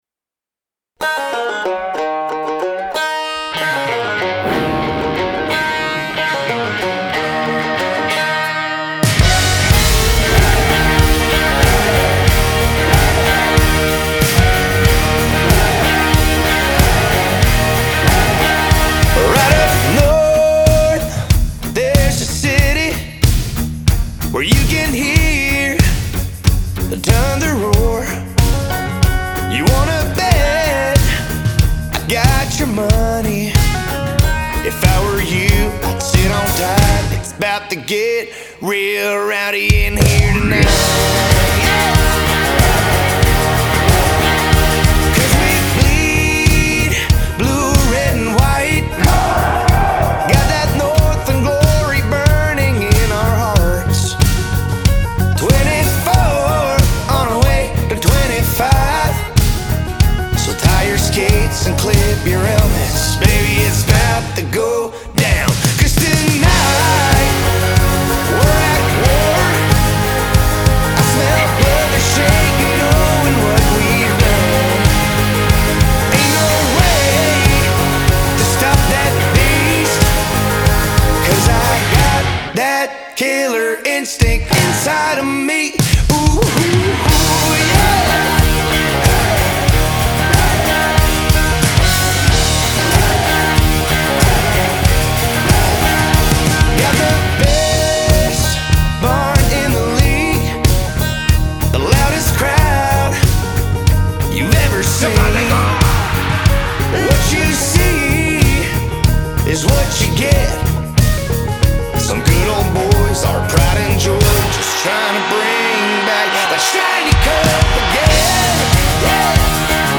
country